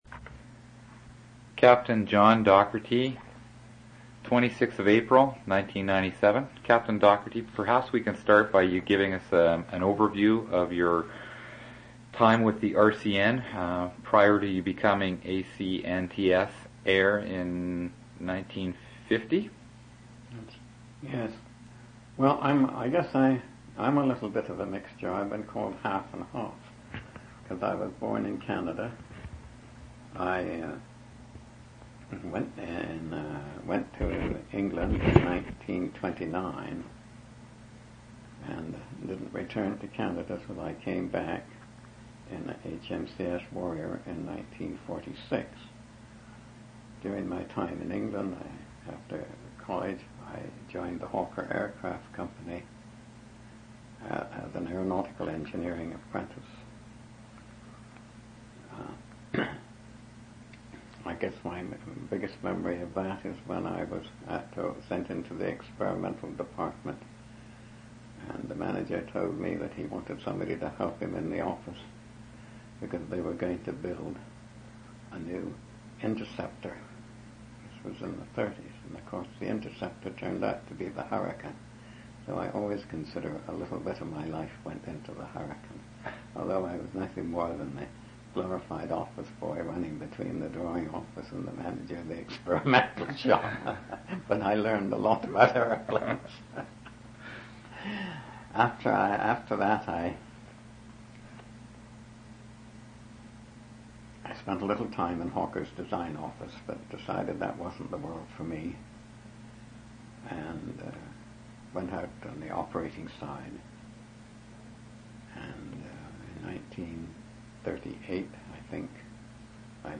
Canadian Military Oral Histories
Two original audio cassettes in Special Collections.
reminiscences interviews oral histories